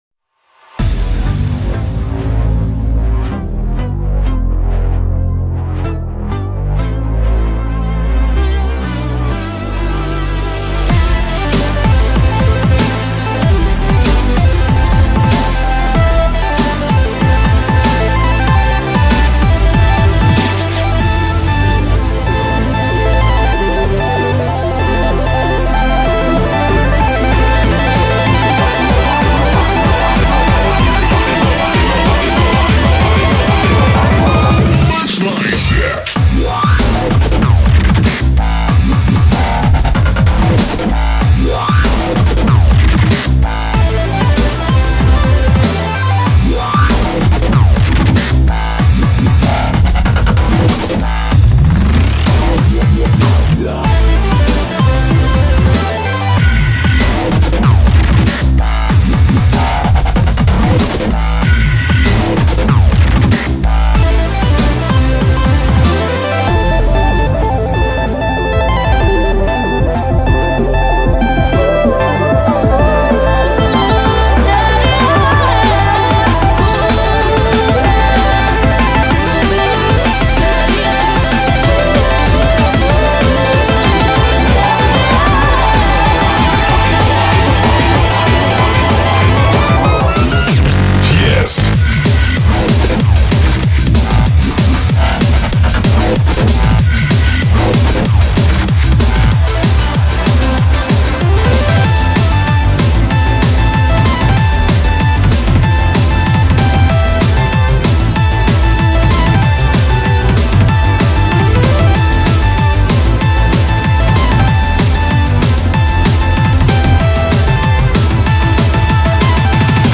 BPM95-190
Audio QualityPerfect (High Quality)
Comments[DUB STEP]